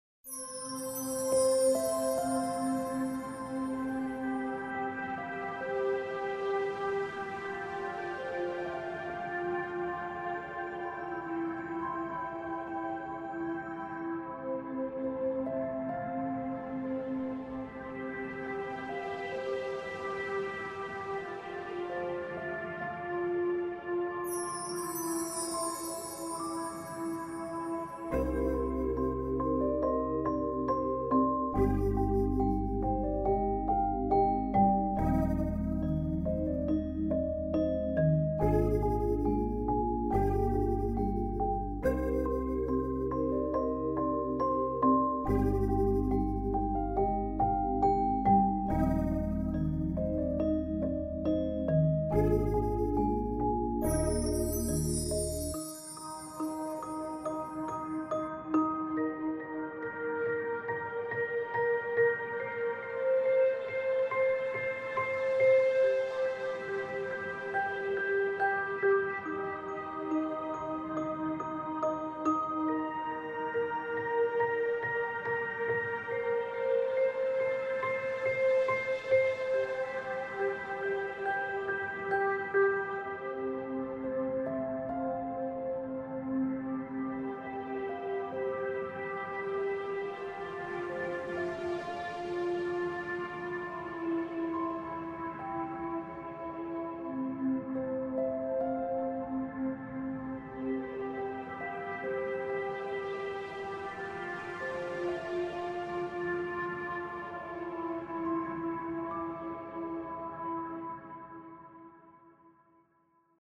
パッドを主体に、ピアノとシンセが重なり合い美しさの中にどこか不安を感じる独特の空気感を表現しています。
• 広がりのあるパッドで世界観を構築
• リバーブを深めに設定し現実離れを演出
• シンプルなフレーズで内面の感情を表現
• 浮遊感と答えのなさを表現